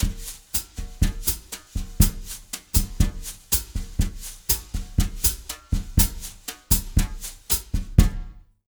120BOSSA01-R.wav